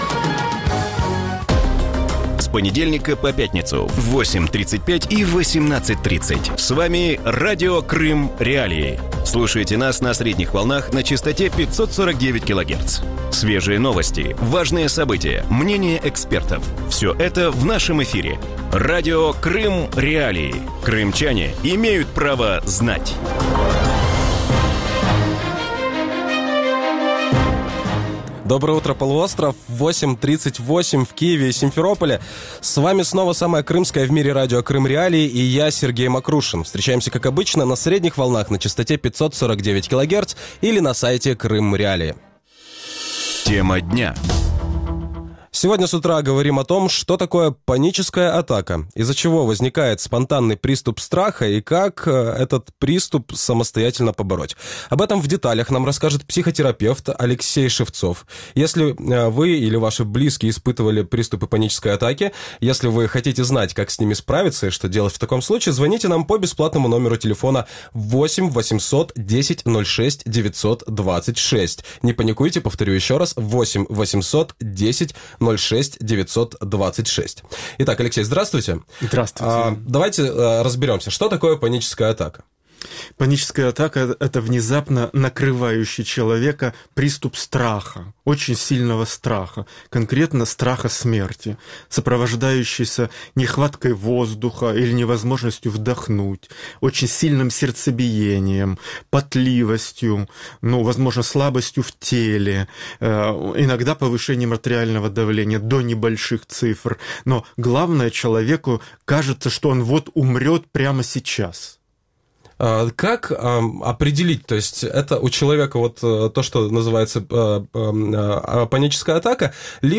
Утром в эфире Радио Крым.Реалии говорят о том, что такое паническая атака, из-за чего возникает спонтанный приступ страха и как ее самостоятельно побороть.